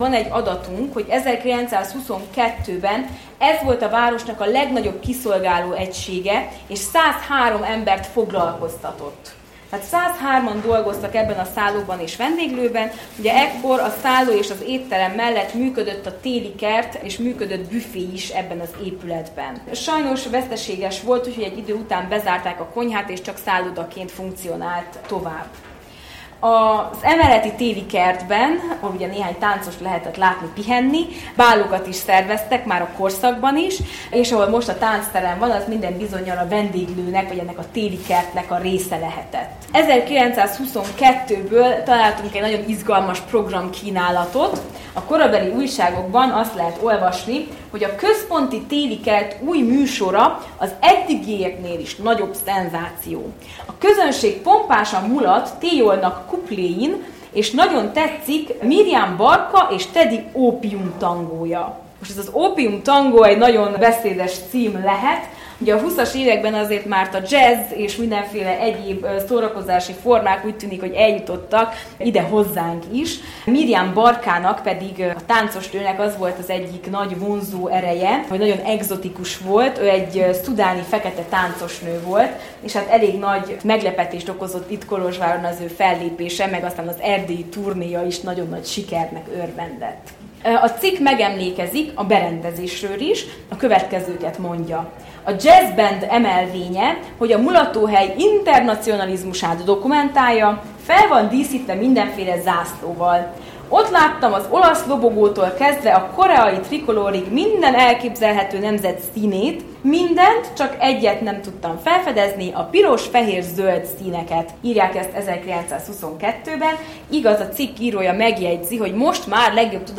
Idén három, különböző korokat érintő sétán vettünk részt, összeállításunkba ezekből válogattunk részleteket.
Az utolsó sétánk a Sapientia EMTE épületébe, az Erdélyi Hagyományok Házának is helyet adó egykori Melody – és még előtte Központi Szálló épületébe vezet.